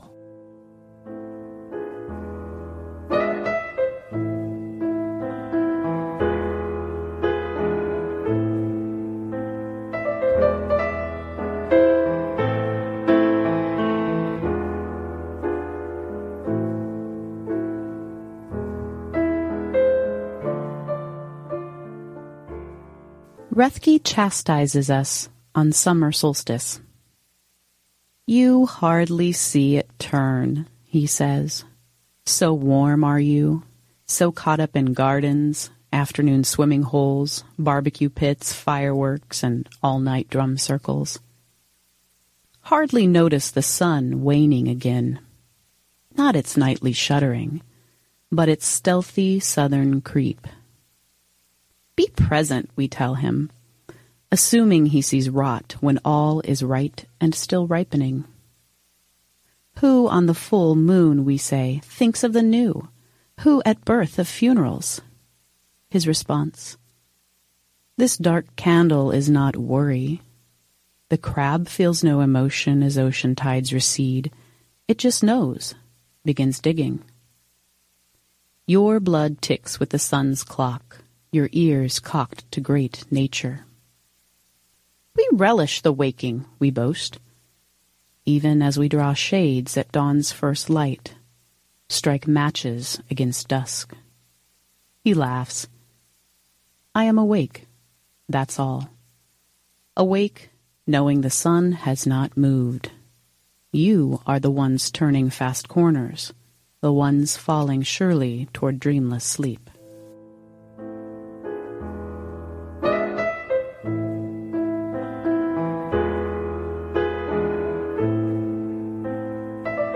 The Platte River Sampler aired on Thursdays from 6PM – 7PM on 89.3 FM KZUM, Lincoln, Nebraska’s non-commercial, listener-sponsored community Radio Station.